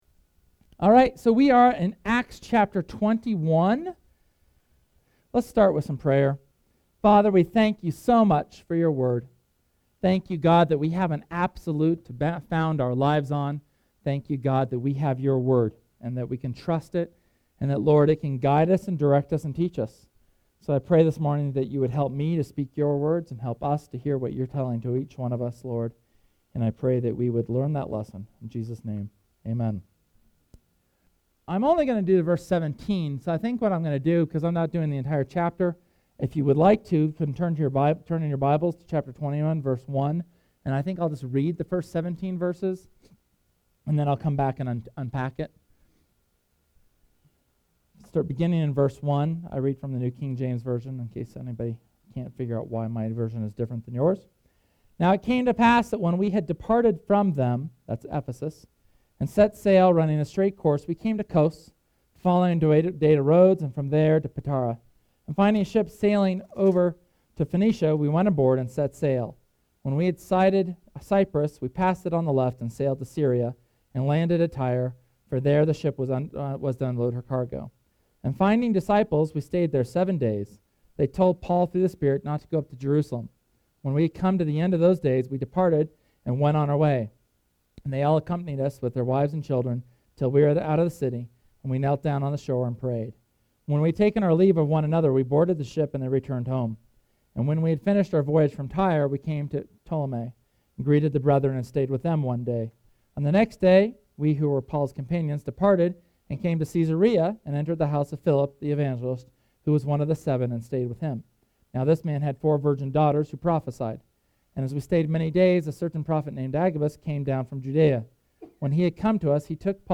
SERMON: Lessons on the Road
Sermon on Acts chapter 21 on the lessons that we can learn from Paul’s trip back to Jerusalem before his arrest.